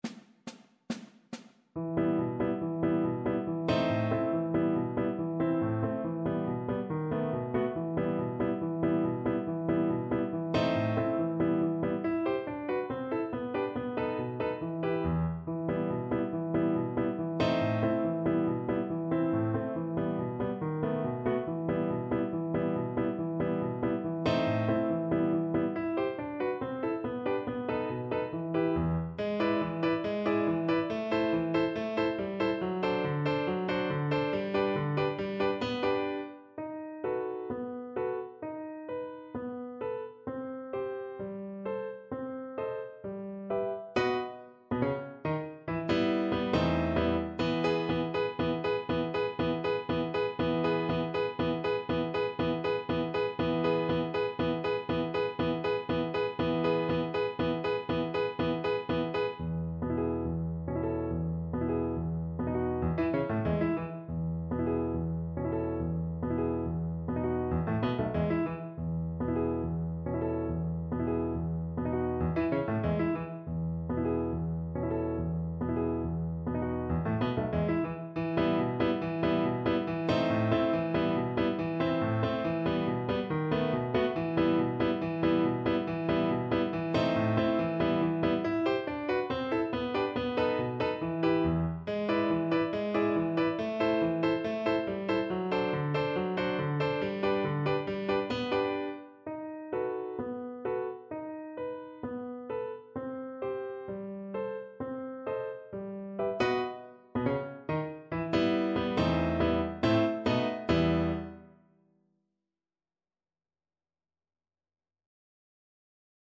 Brahms: Taniec węgierski nr 5 (na flet i fortepian)
Symulacja akompaniamentu